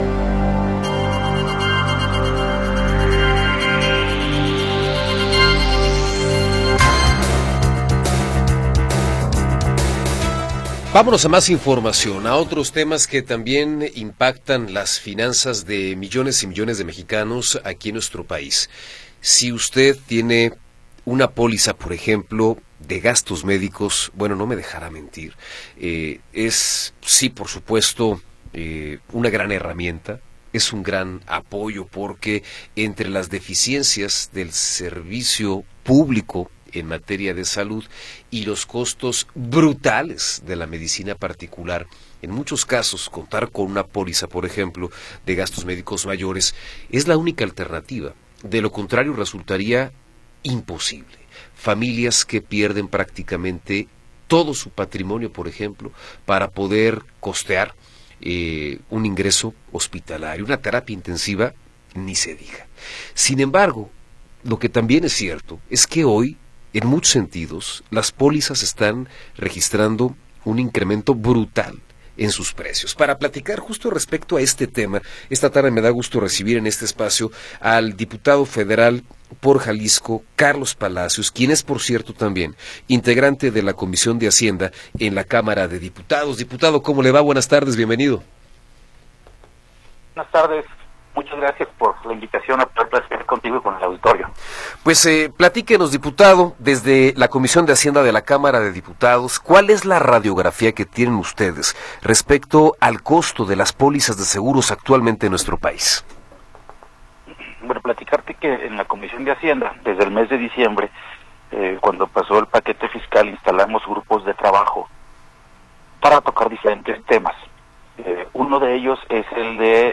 Entrevista con Carlos Palacios Rodríguez